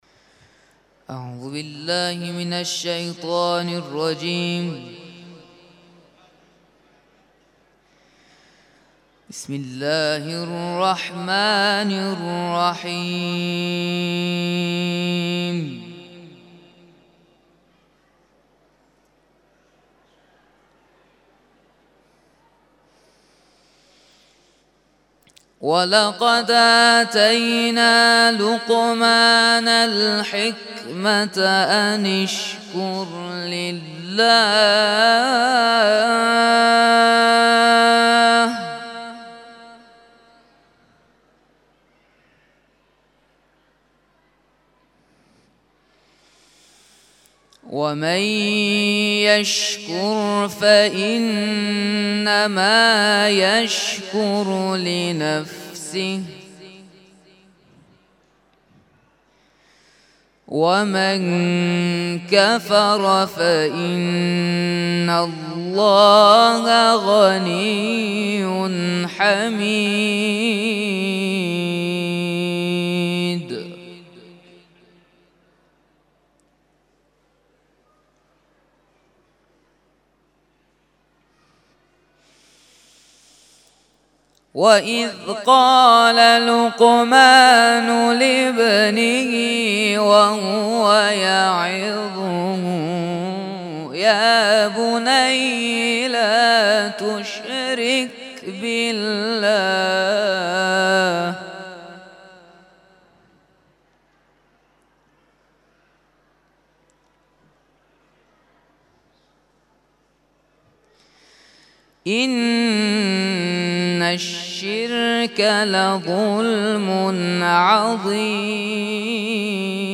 تلاوت ظهر - سوره لقمان آیات ( ۱۲ الی ۱۵ ) Download